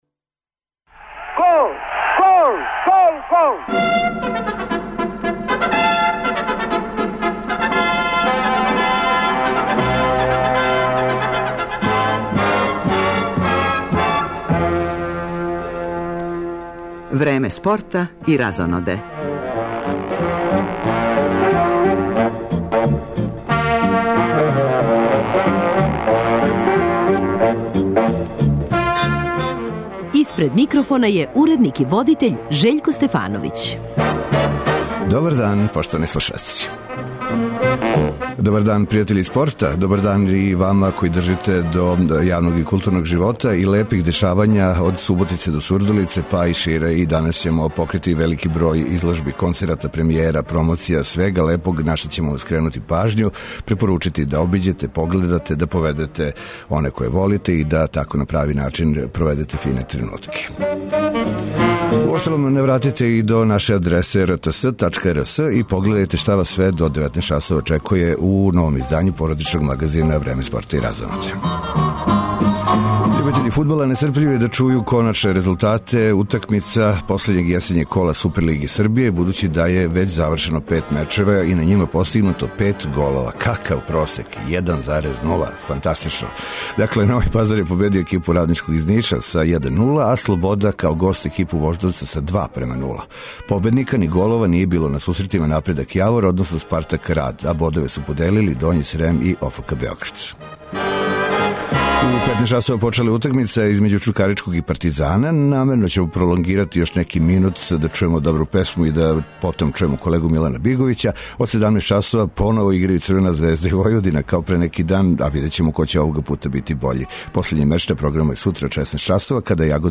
Утакмице првог кола Светског првенства за рукометашице, чији је домаћин наша земља, играће се данас у Нишу, Зрењанину, Новом Саду и Београду, па ће се наши репортери јављати са актуелним информацијама и резултатима из свих група. Пратимо и последње коло јесењег дела Супер лиге Србије у фудбалу, с нагласком на дерби сусрет између Црвене звезде и Војводине.